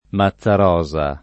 Mazzarosa [ ma ZZ ar 0@ a ] cogn.